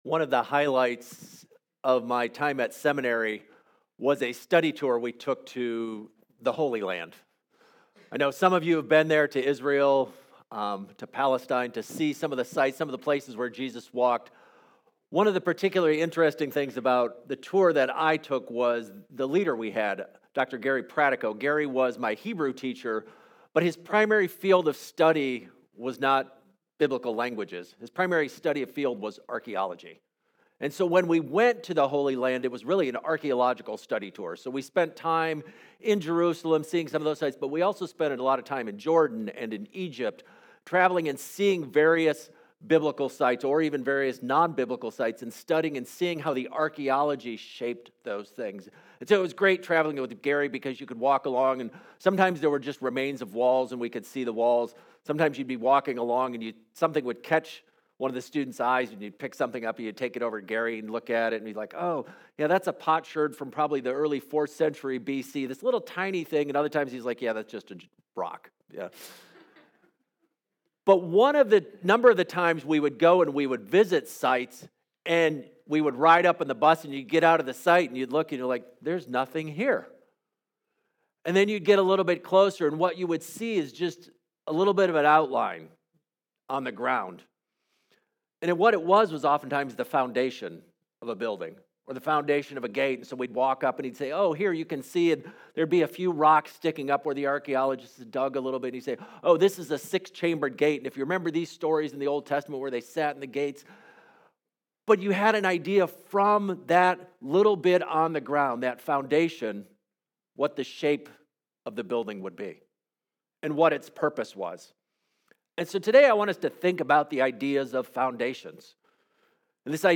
The sermon emphasizes that we are God's temple collectively rather than individually - how does this shift in perspective change the way we view conflict and unity within the church?